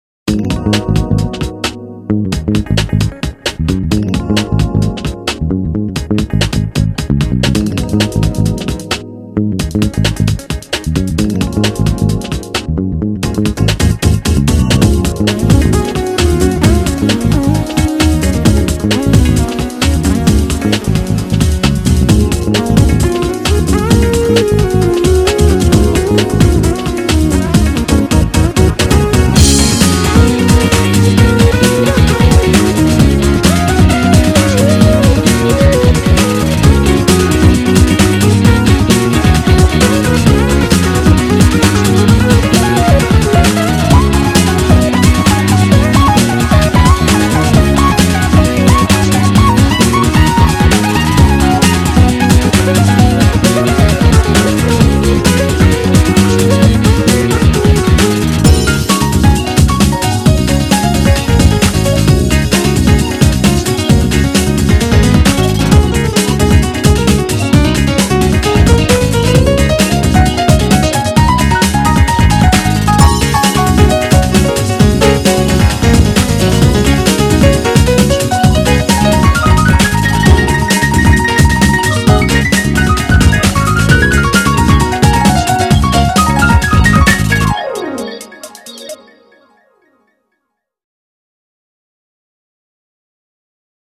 퓨젼계열곡인데 신납니다.